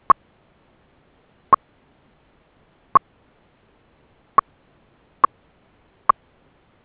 VHF Beacon
Sounds of all beacon modes: